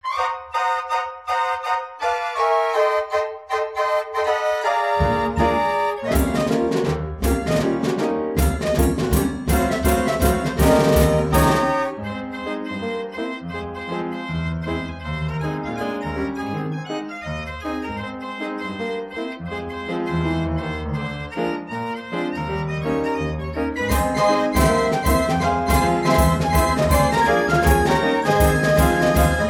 * NICKELODEONS!
*CREMONA ORCHESTRAL STYLE J